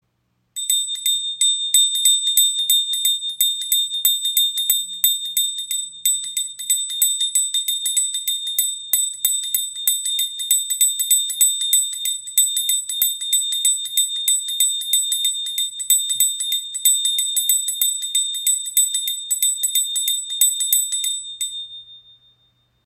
• Icon Reiner Klang – Klarer, heller Ton für Meditation & Rituale
Eine kleine Handbewegung, ein heller, vibrierender Ton – die Nandi-Handglocke entfaltet ihren Klang und schafft eine Atmosphäre der Klarheit und Achtsamkeit.
• Material: Messing